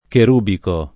[ ker 2 biko ]